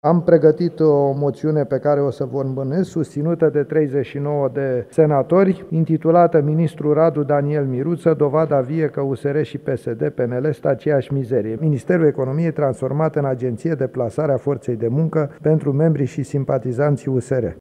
Senatorul AUR, Petrișor Peiu, la începutul ședinței de plen a Senatului: Moțiunea este susținută de 39 de senatori